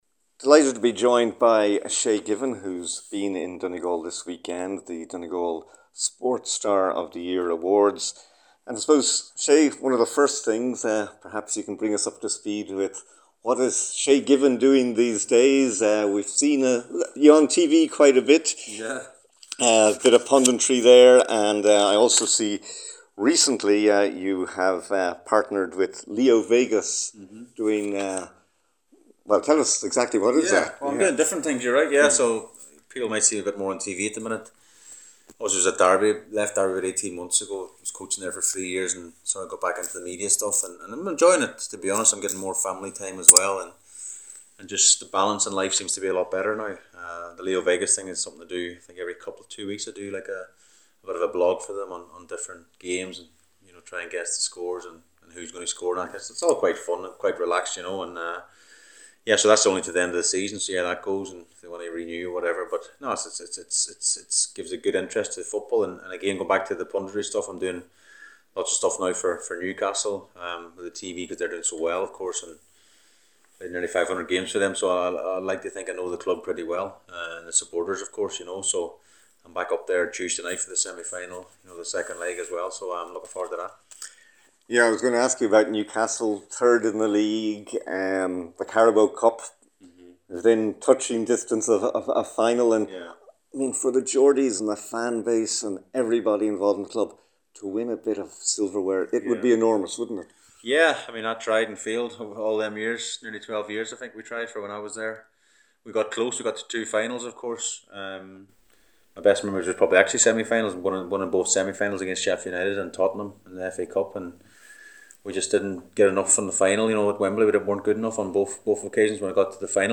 Former Republic of Ireland goalkeeper Shay Given was the special guest at the 2022 Donegal Sports Star Awards which were held in the Mount Errigal Hotel, Letterkenny.